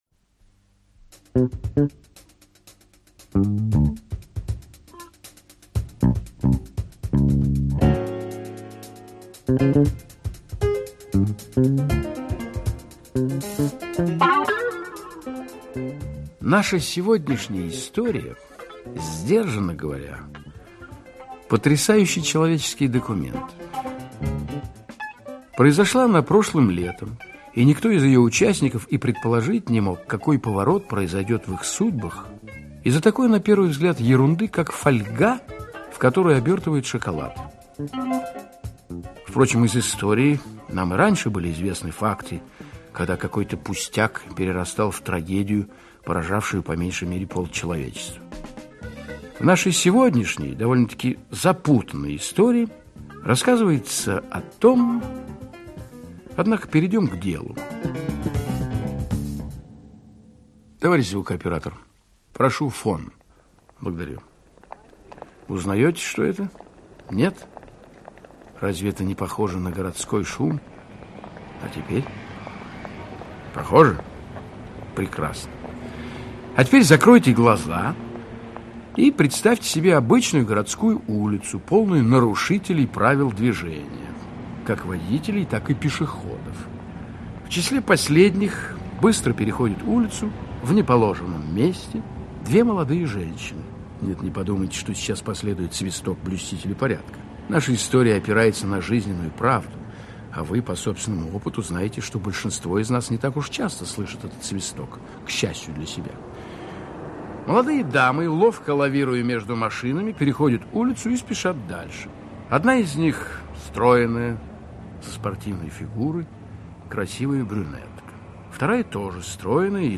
Aудиокнига Фольга Автор Арди Лийвес Читает аудиокнигу Актерский коллектив.